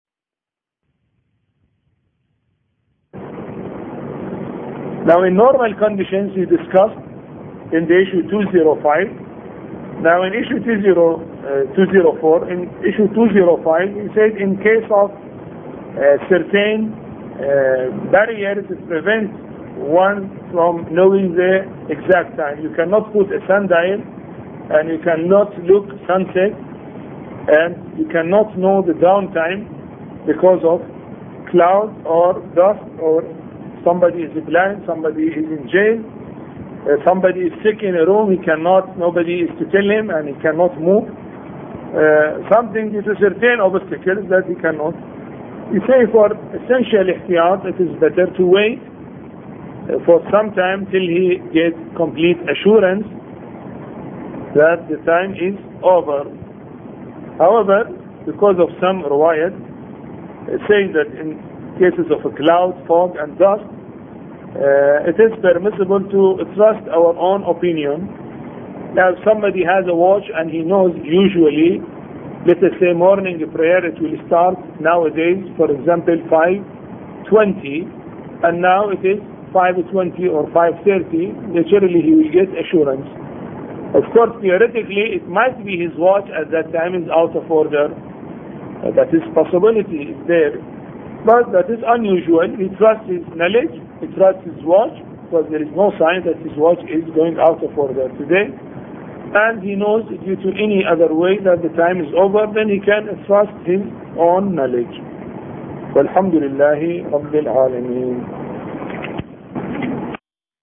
A Course on Fiqh Lecture 13